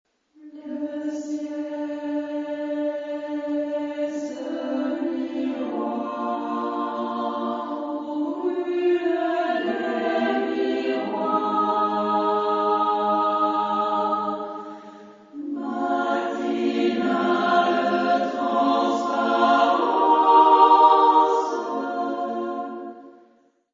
Género/Estilo/Forma: Profano ; Poema ; contemporáneo
Tipo de formación coral: SSAA  (4 voces Coro femenino )
Tonalidad : polimodal
Consultable bajo : 20ème Profane Acappella